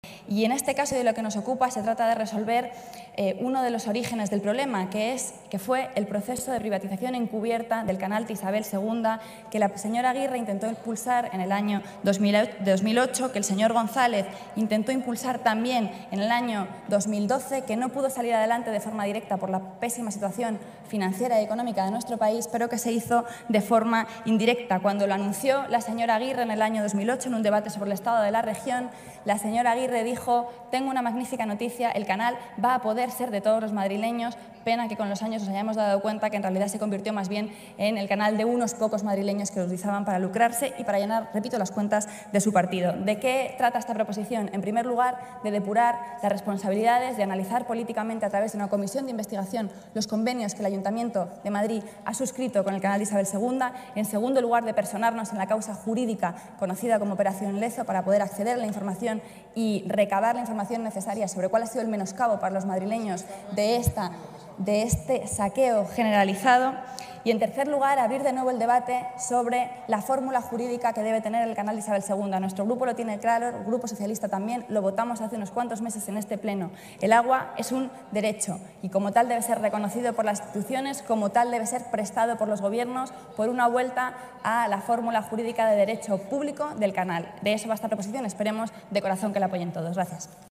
Nueva ventana:Rita Maestre habla sobre las razones de esta proposición
RMaestrePlenoRazonesParaUnaProposicionCanal-26-04.mp3